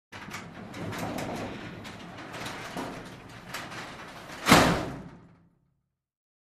Garage Door
fo_garagedr_sm_close_02_hpx
Large and small garage doors are opened and closed.